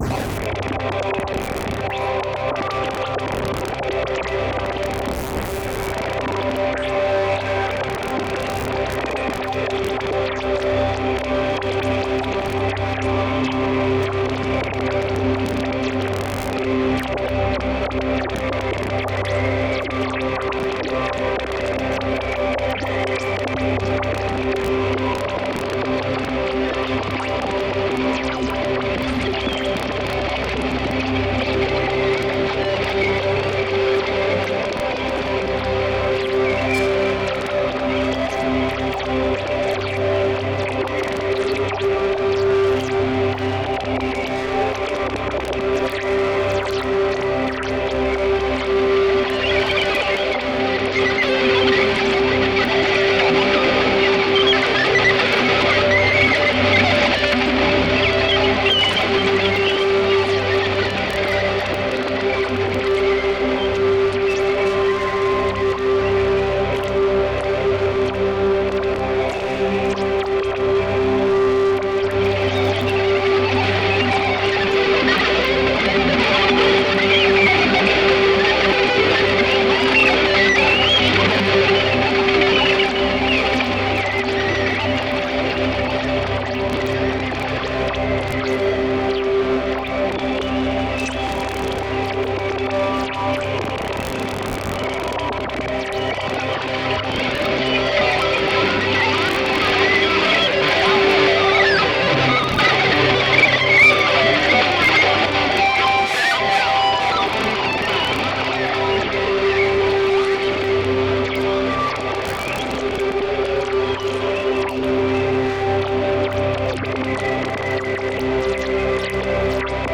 深い熟聴、Deep Listeningを誘う心地よい音響体験が、ここにあると感じました。
本作はたいへん複雑なギター・オーケストレーション作品となっています。
様々なエフェクト処理が施されたギターが絡み合いますが、特筆すべきことの一つにプリペアード・ギターの多用があります。
また非常に高速なパンニングも特徴の一つです。
人間が認識できる限界に近い速度で、ギター音が左右に激しく移動します。
ギター愛好家の方々にはもちろん、現代音楽、先端的テクノ、実験音楽をお好きな方々にもお薦めのアルバムです。